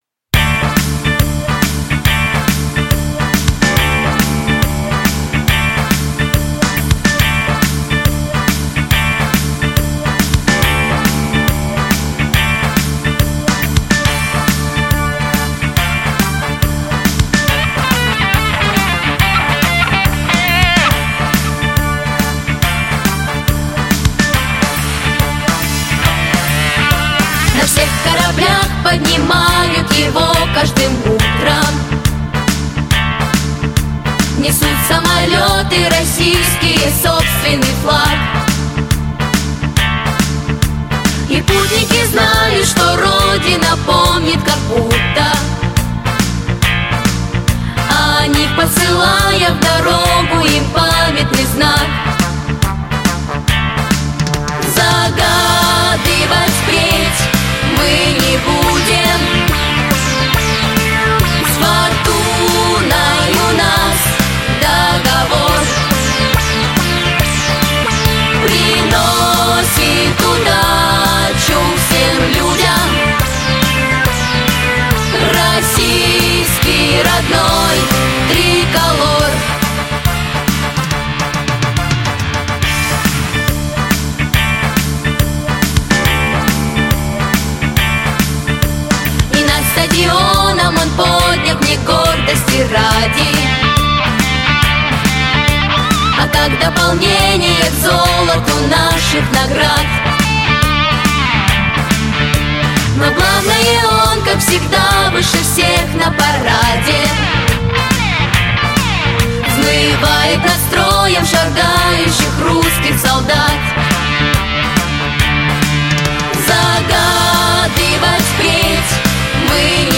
• Категория: Детские песни
патриотическая
Детская эстрадная вокальная студия.